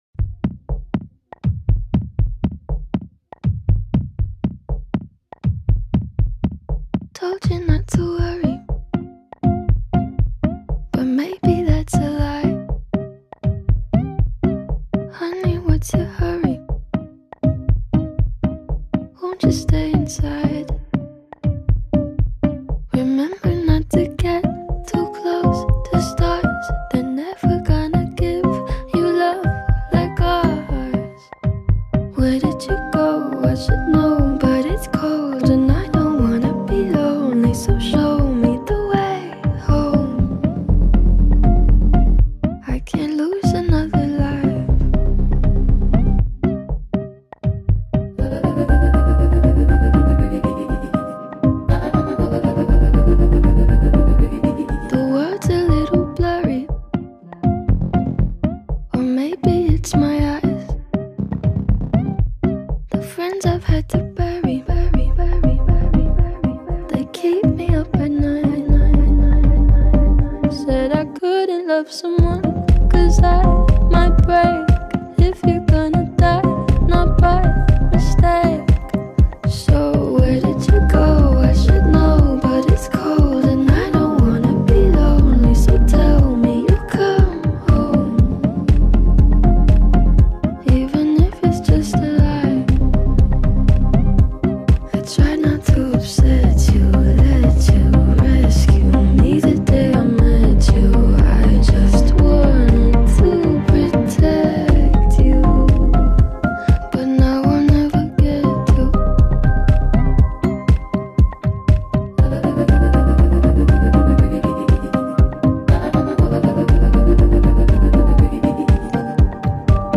نسخه اصلی